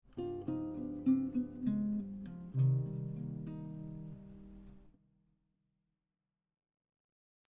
Here are some application examples over a G7 b9 chord to a C minor 7 chord including guitar tabs and audio.
Descending line 1
This descending line example starts on the 7th chord tone of the G7 b9 and resolves to the b3 chord tone of C minor 7.